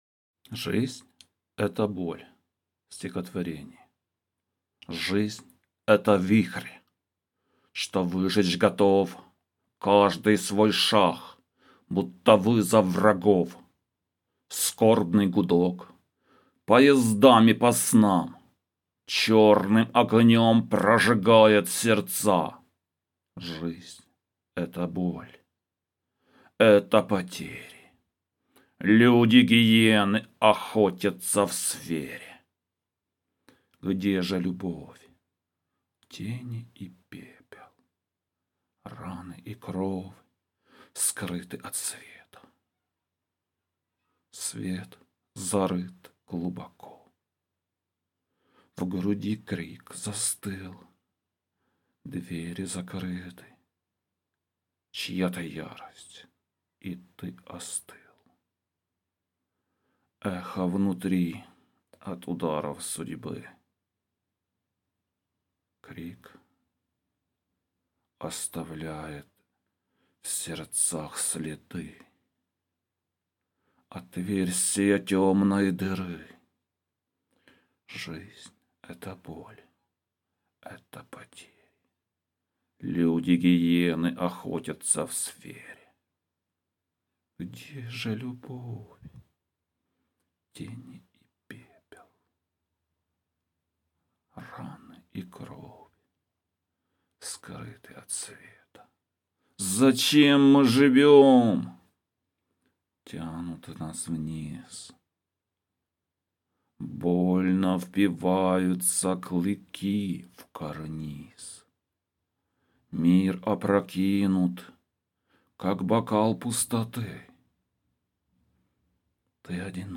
ТИП: Поезія
Гарно прочитали, проникливо... hi